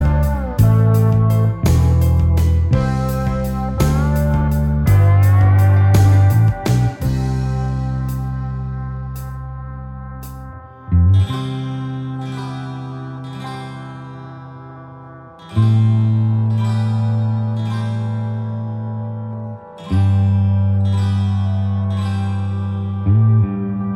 Minus Lead Guitar Indie / Alternative 3:24 Buy £1.50